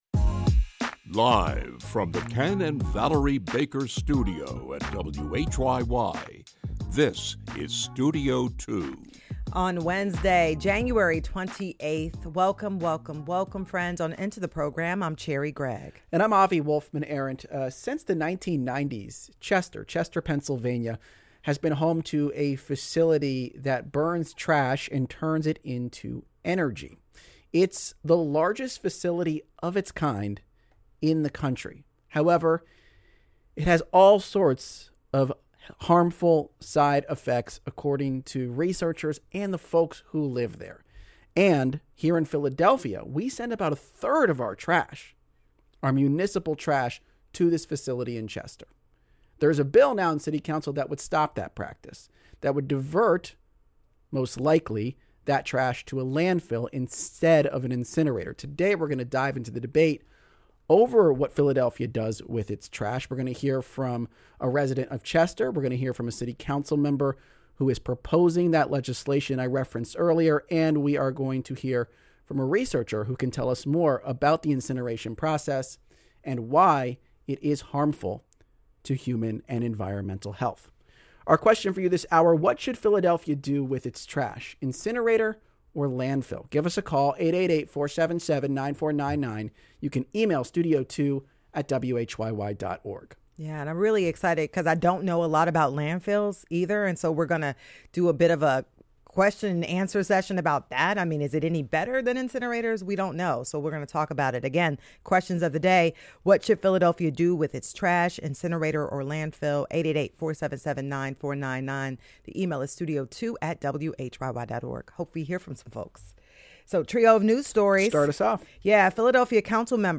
Plus, a studio audience joins them in a lively and personal question and answer session.